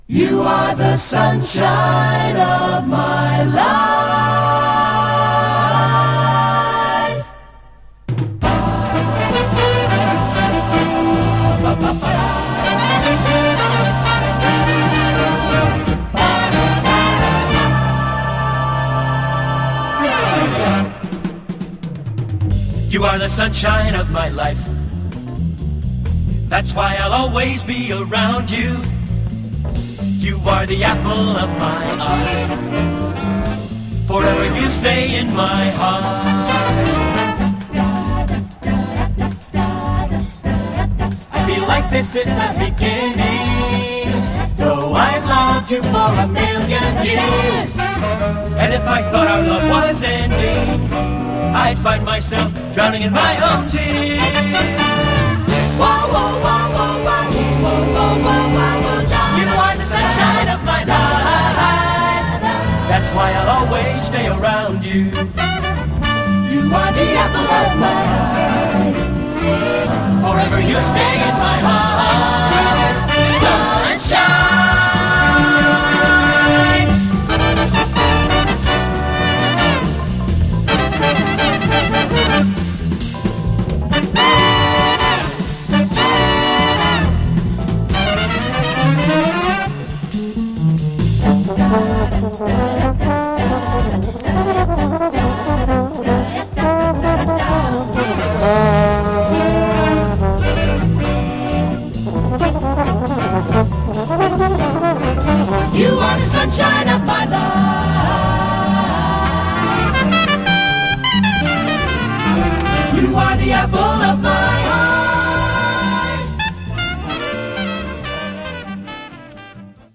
Jazz Vocal us